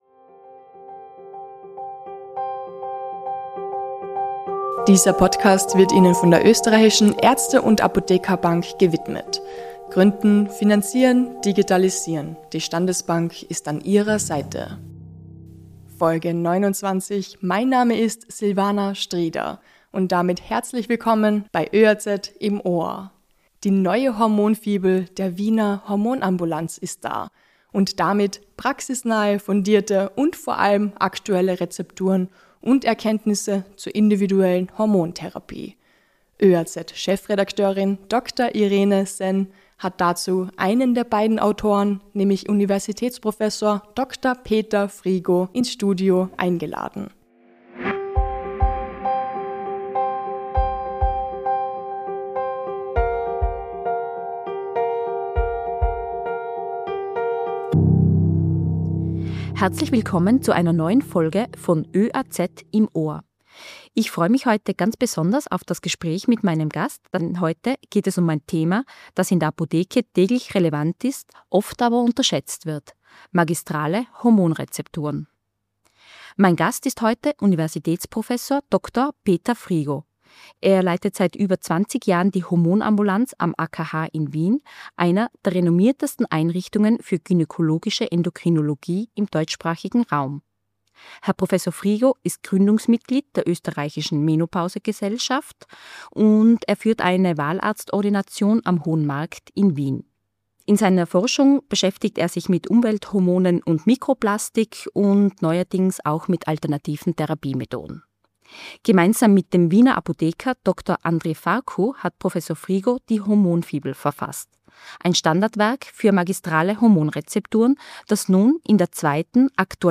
ins Podcast-Studio eingeladen. Der Experte erklärt, wann magistrale Zubereitungen Fertigarzneimitteln überlegen sind, wie Apotheker:innen mit wenig Aufwand individuelle Therapien ermöglichen können und liefert Fakten zu bioidenten Hormonen.